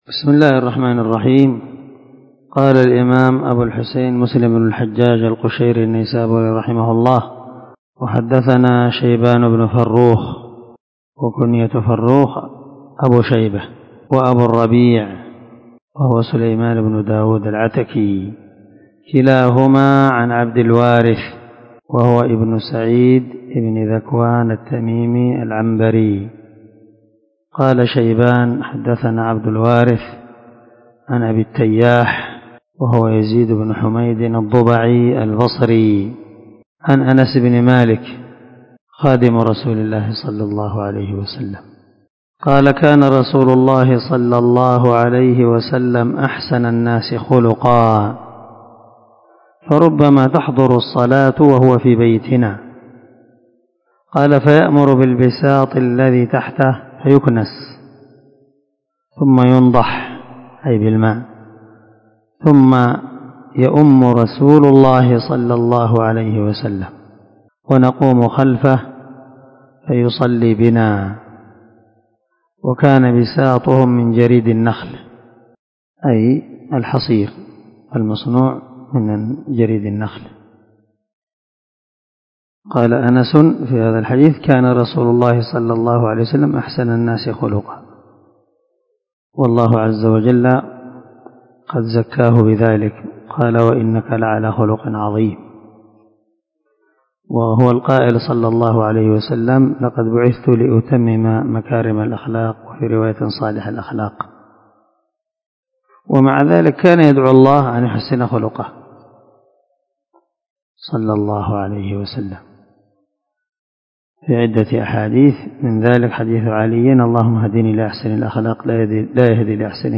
419الدرس 91 من شرح كتاب المساجد ومواضع الصلاة حديث رقم ( 659 – 661 ) من صحيح مسلم